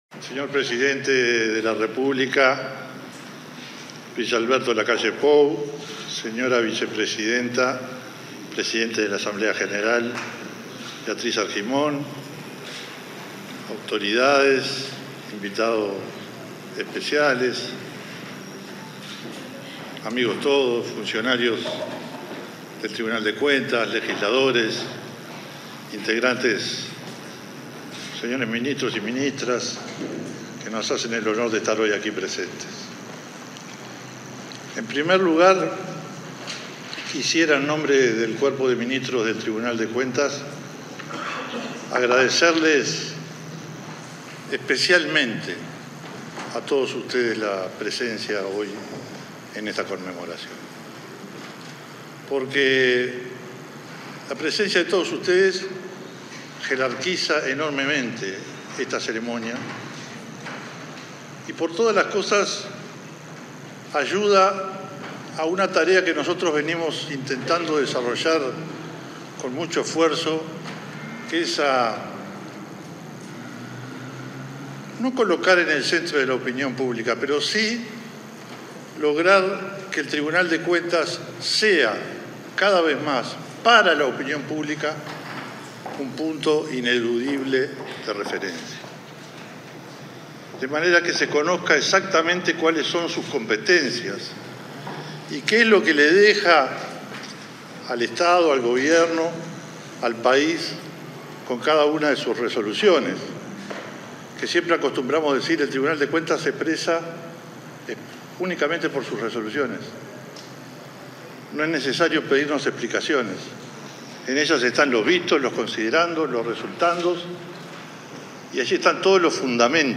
Palabras del presidente del Tribunal de Cuentas, Francisco Gallinal
Al conmemorarse el 90.° aniversario del Tribunal de Cuentas, este 13 de agosto, se expresó su presidente, Francisco Gallinal.